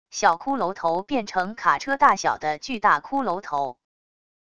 小骷髅头变成卡车大小的巨大骷髅头wav音频